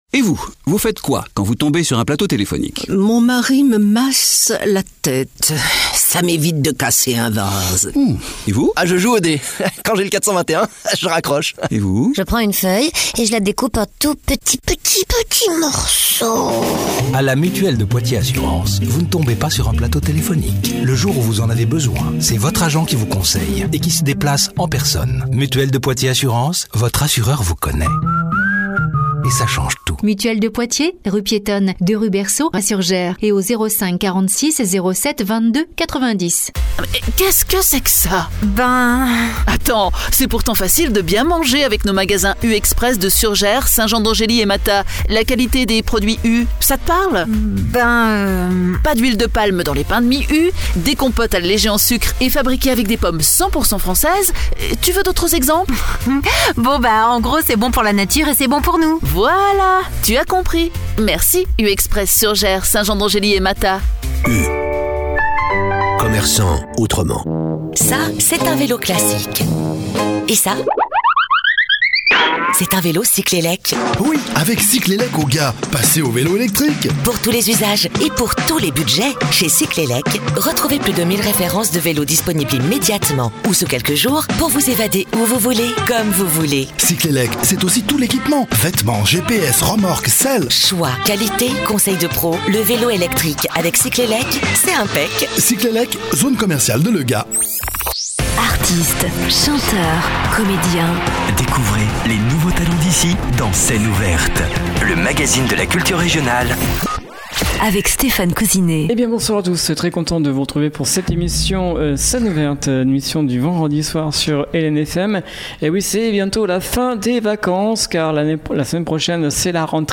au chant
à la guitare